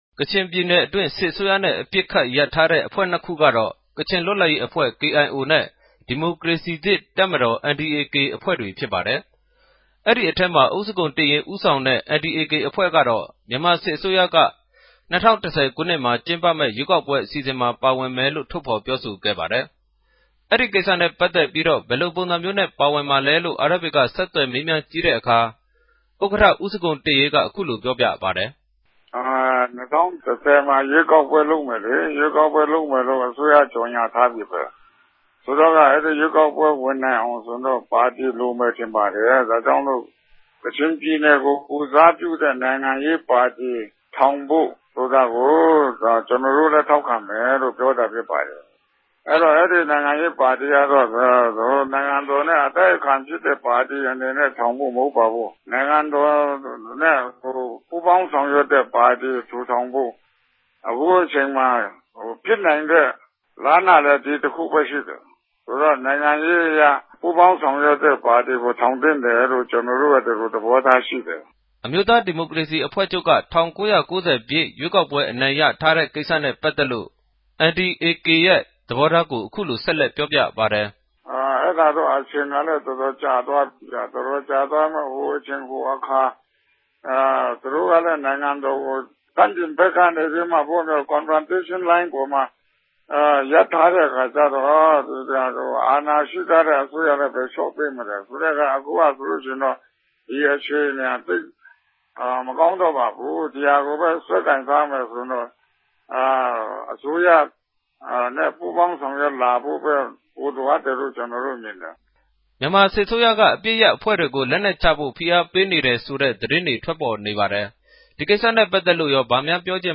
သတင်းပေးပိုႛခဵက်။်